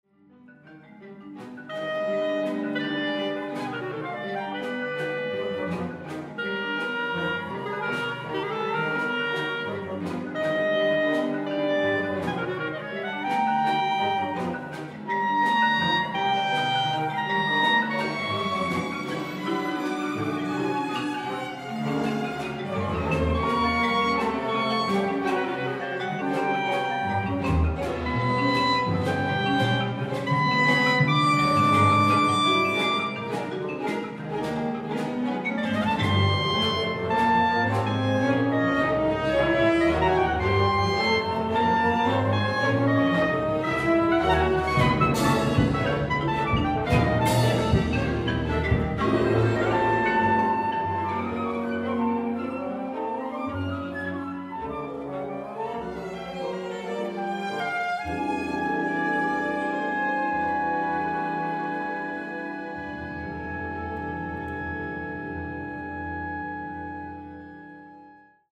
Concierto para clarinete